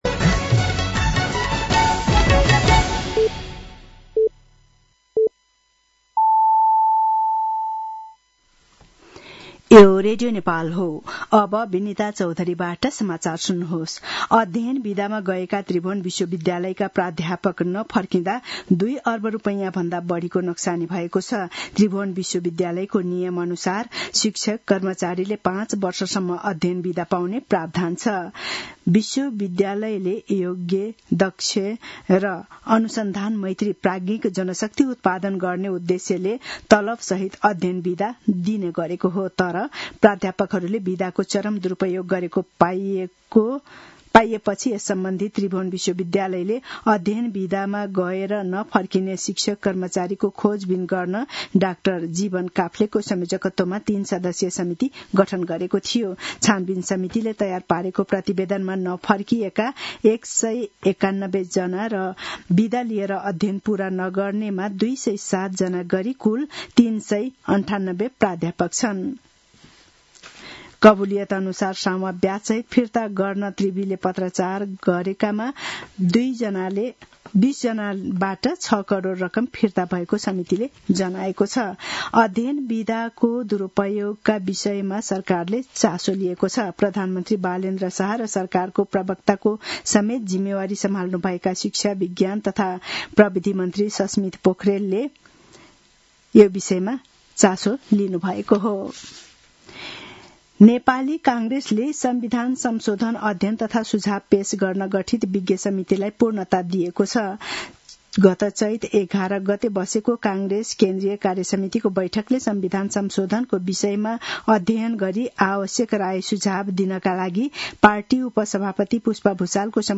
साँझ ५ बजेको नेपाली समाचार : ६ वैशाख , २०८३
5-pm-nepali-news-1-06.mp3